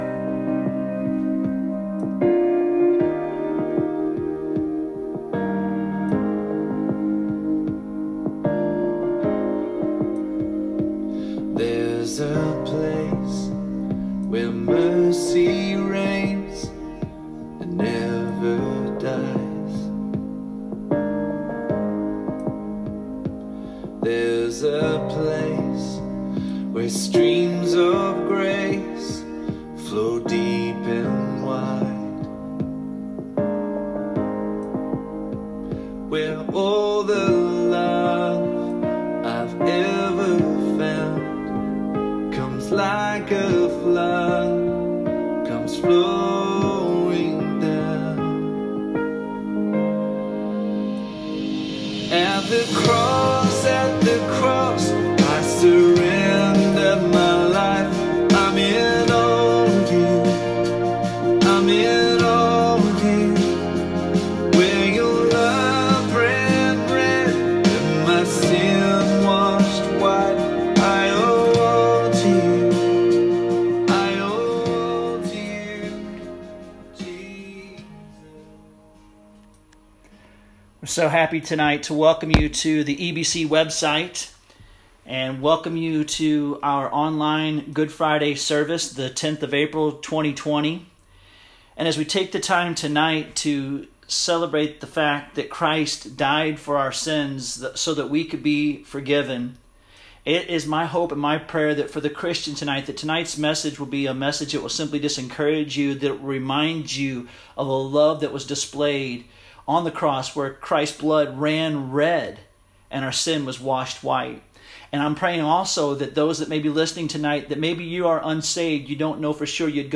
Good Friday Sermon